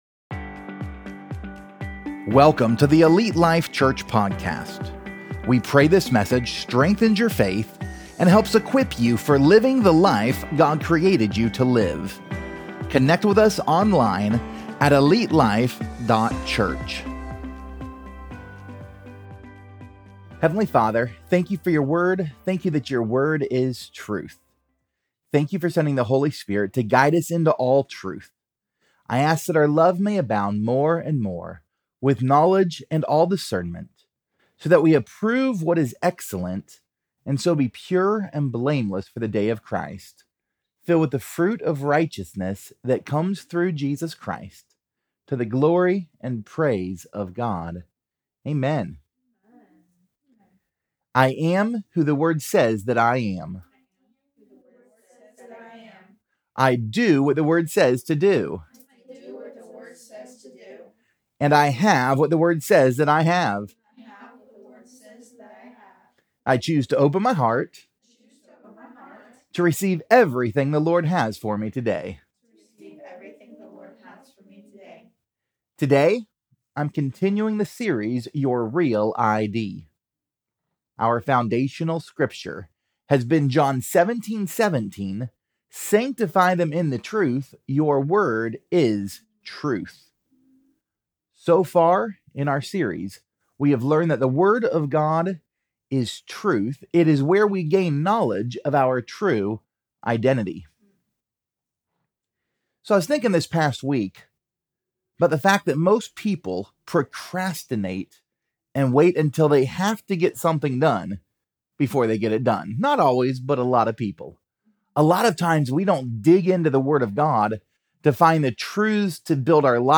Pt 15: Salt Life | Your REAL ID Sermon Series